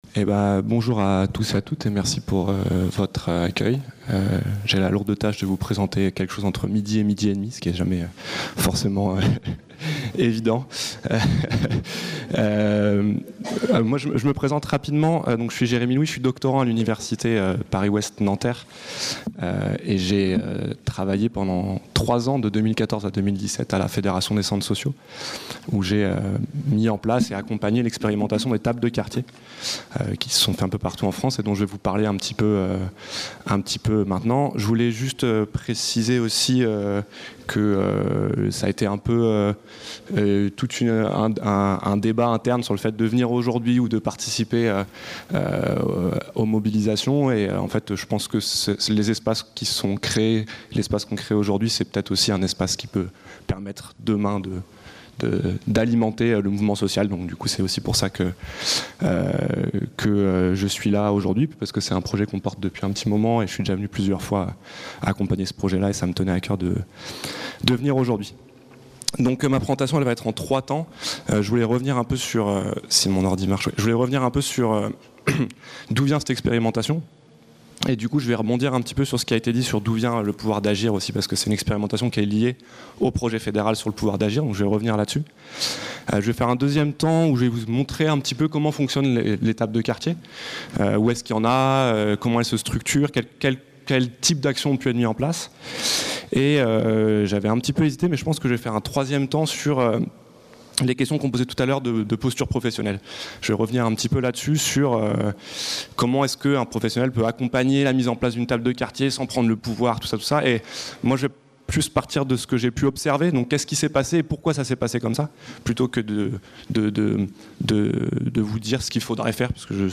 06- (Table ronde 1)